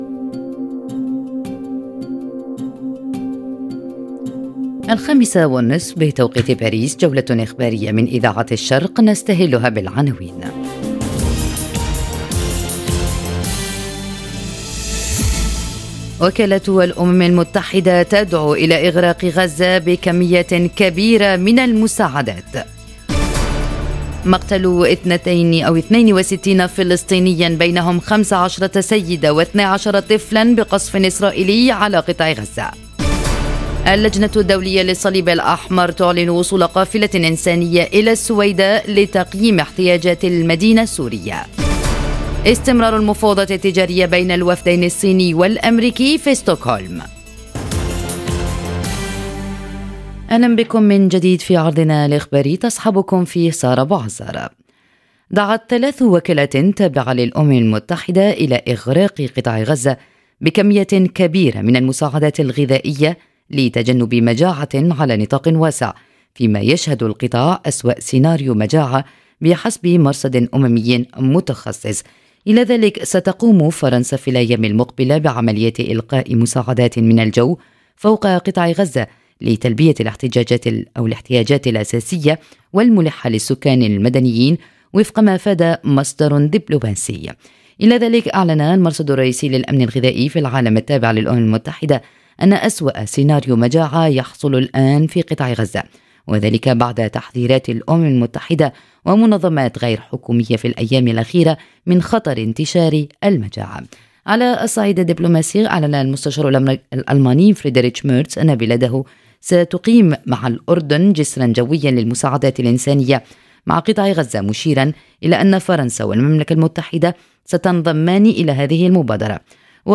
نشرة أخبار المساء: أزمات إنسانية وسياسية متصاعدة: غزة تحت الحصار، ومفاوضات تجارية بين أمريكا والصين - Radio ORIENT، إذاعة الشرق من باريس